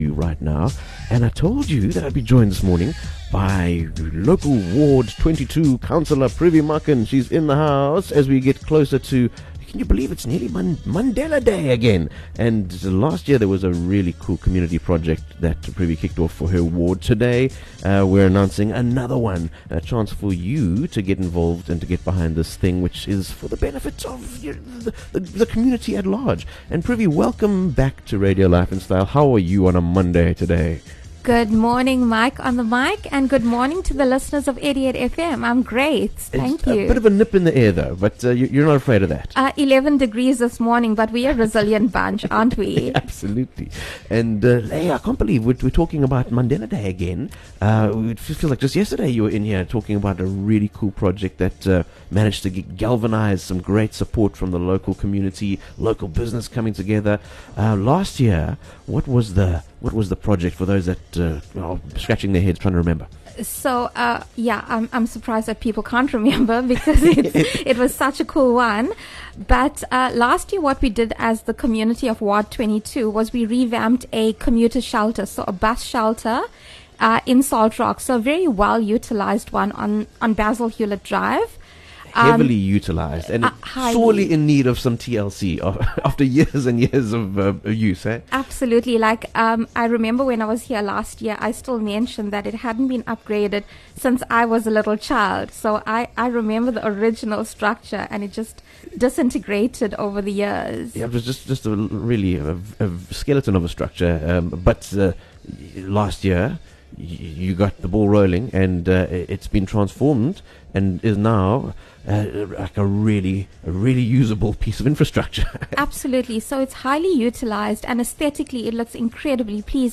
Local Ward 22 Councillor Privi Makhan popped in to the Radio Life & Style Studio to announce her 2023 community project for Mandela Day, as well as to invite local residents and businesses to get behind the plan to deliver a much-needed upgrade to public transport commuter facilities in Umhlali Village.